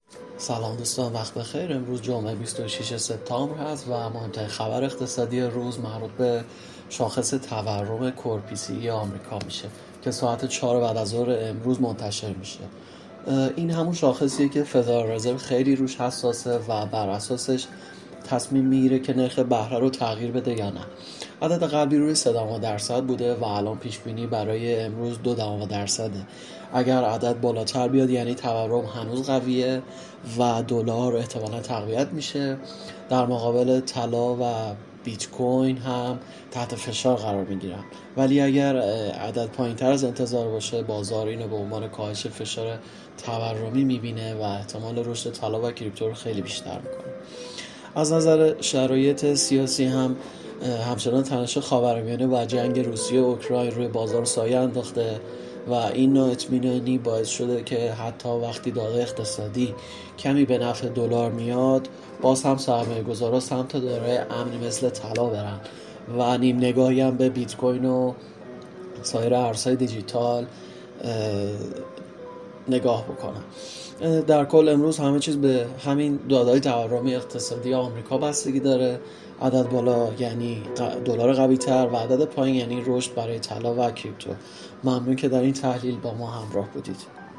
🔸گروه مالی و تحلیلی ایگل با تحلیل‌های صوتی روزانه در خدمت شماست!